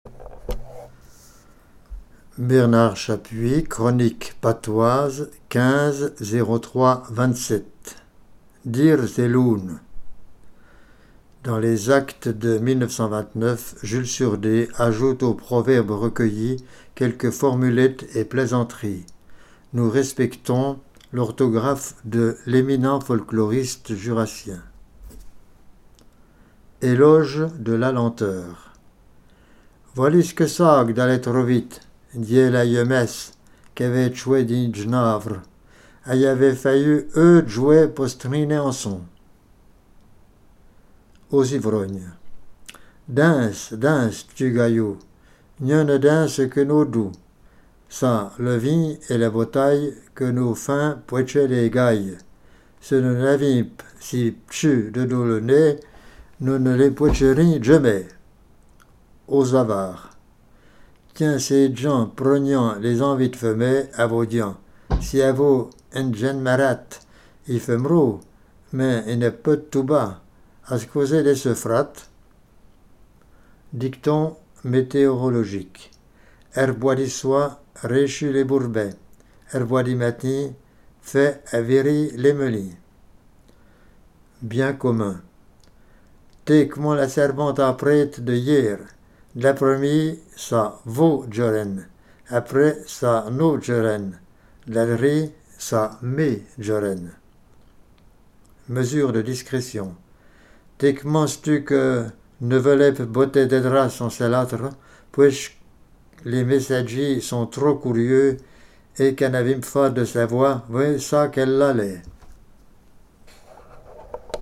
Djasans Patois Jurassien